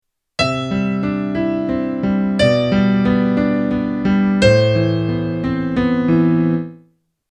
Here’s another example where the melody still conforms to the guidelines in Step 1, but using a different melody note for the G chord in the second bar.
Again this works really well.